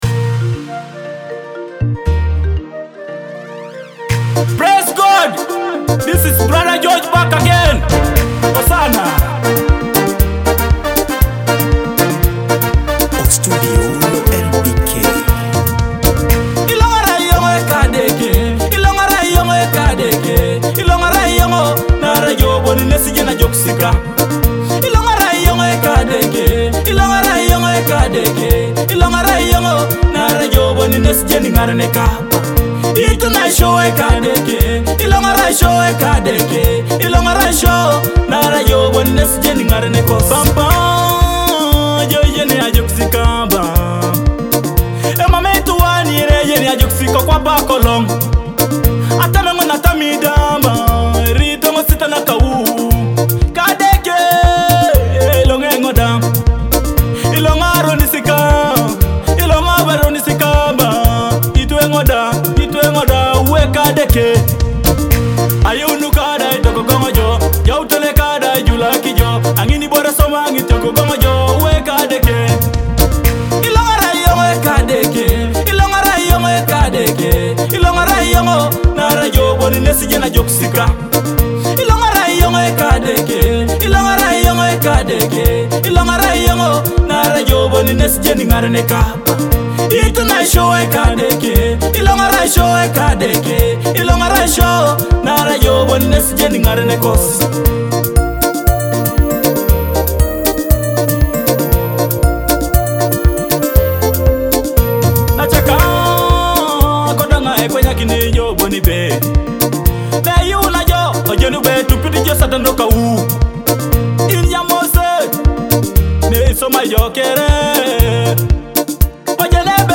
heartfelt Teso gospel song
powerful Teso gospel song